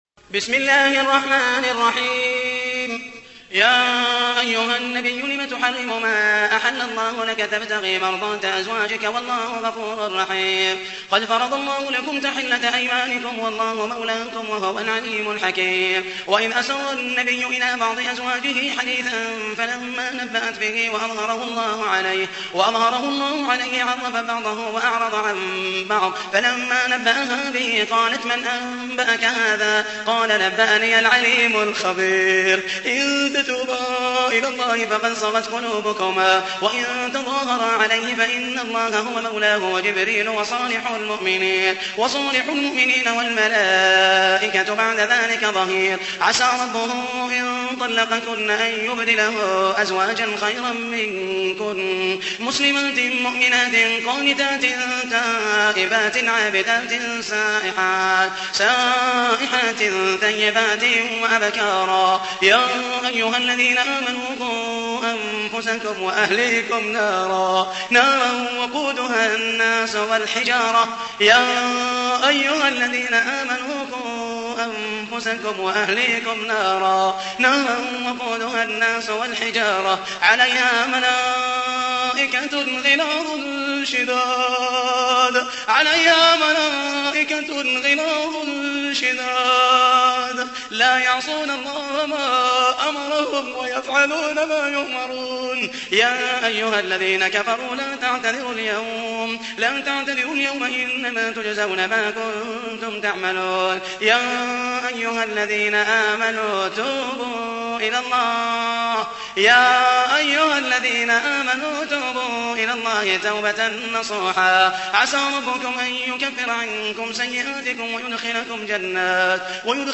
تحميل : 66. سورة التحريم / القارئ محمد المحيسني / القرآن الكريم / موقع يا حسين